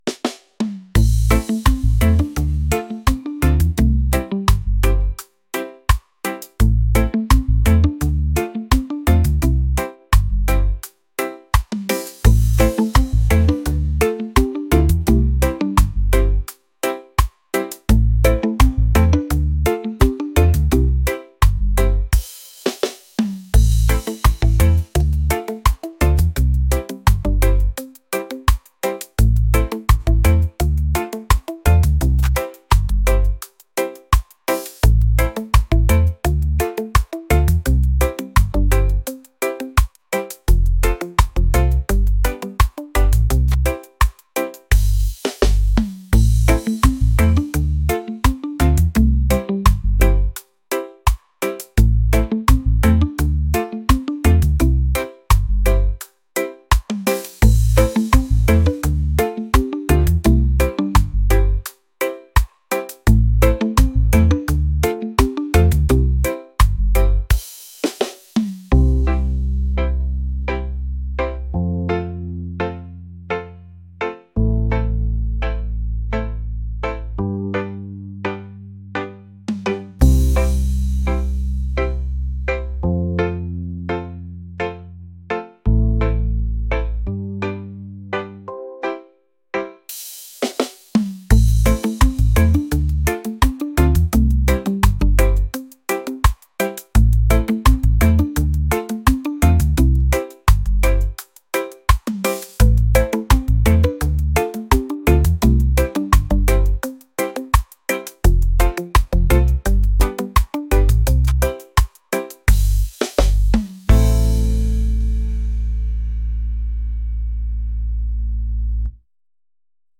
reggae | latin | lounge